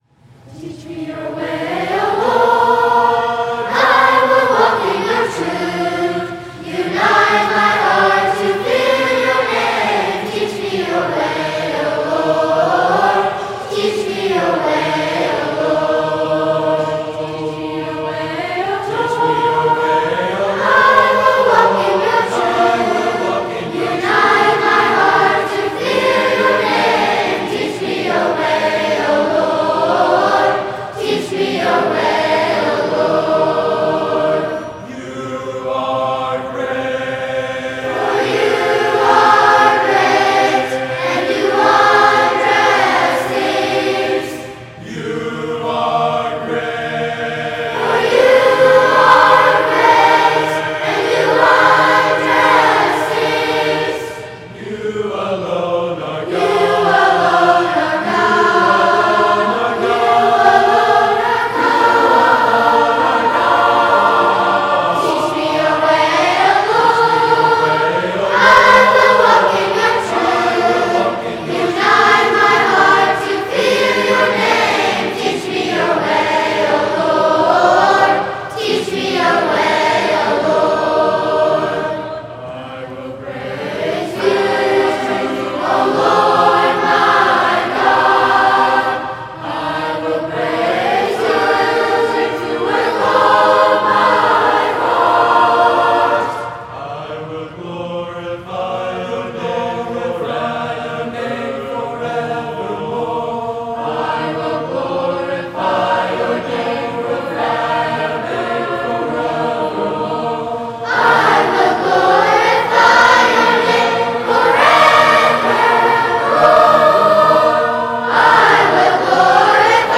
Voicing: SAT(B), a cappella